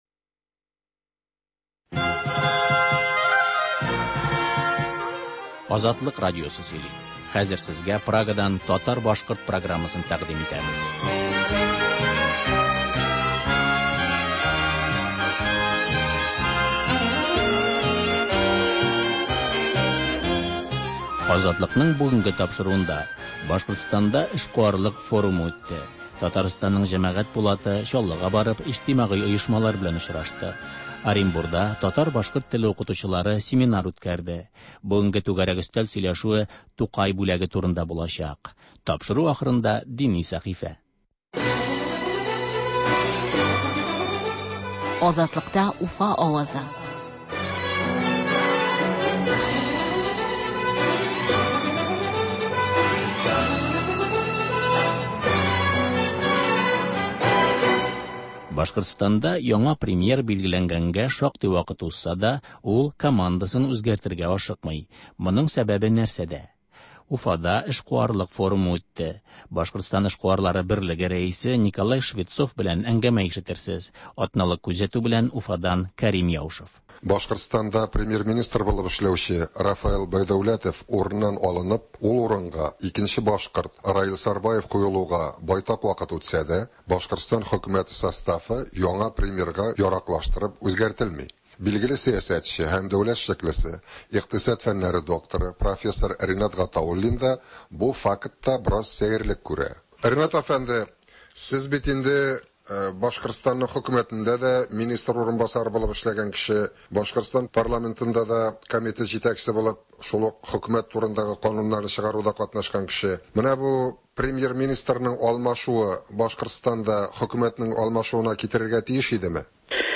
Азатлык радиосы бар атнага күз сала - Башкортстаннан атналык күзәтү - түгәрәк өстәл артында сөйләшү